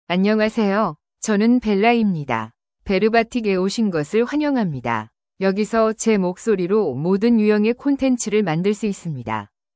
Bella — Female Korean (Korea) AI Voice | TTS, Voice Cloning & Video | Verbatik AI
Bella is a female AI voice for Korean (Korea).
Voice sample
Listen to Bella's female Korean voice.
Bella delivers clear pronunciation with authentic Korea Korean intonation, making your content sound professionally produced.